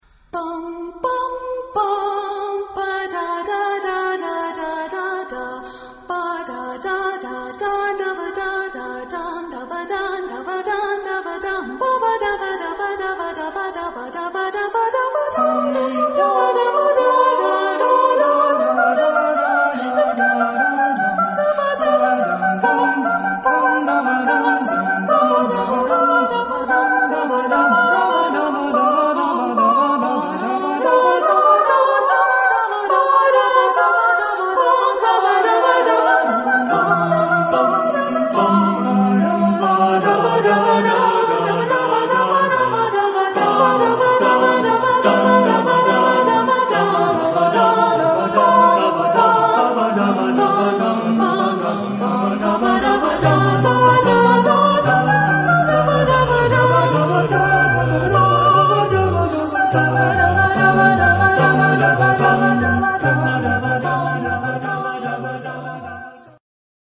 SSAATTBB (8 voix mixtes).
Baroque. Jazz vocal.
Tonalité : mi mineur